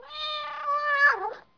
جلوه های صوتی
دانلود صدای حیوانات جنگلی 21 از ساعد نیوز با لینک مستقیم و کیفیت بالا